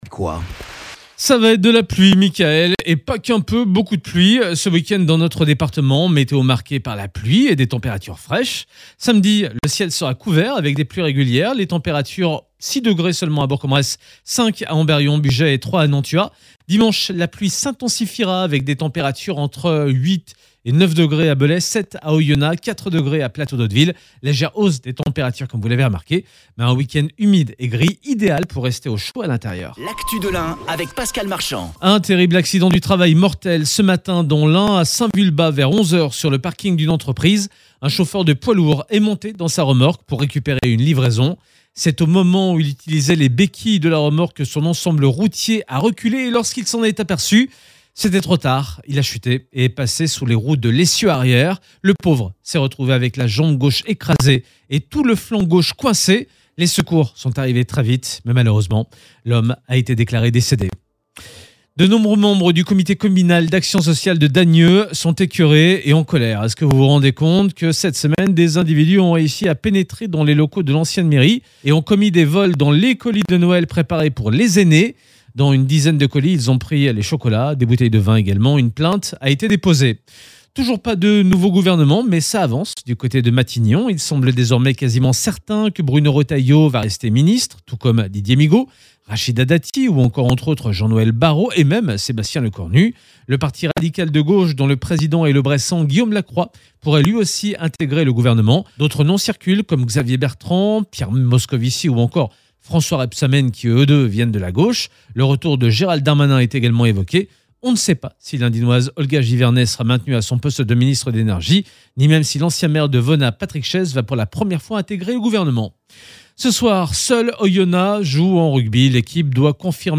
Écoutez le dernier flash info